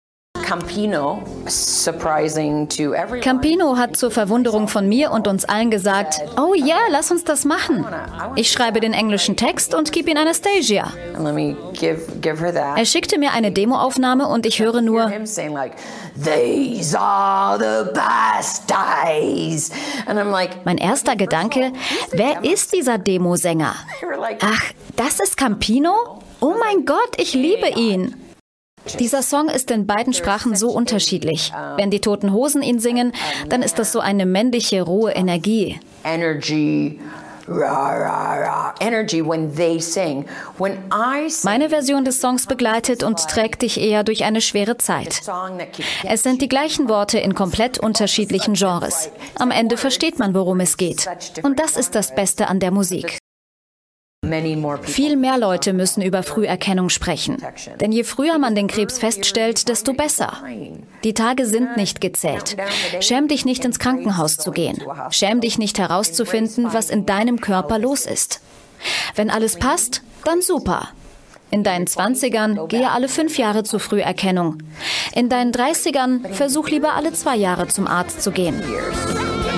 TV Spot - Renault Clio Muse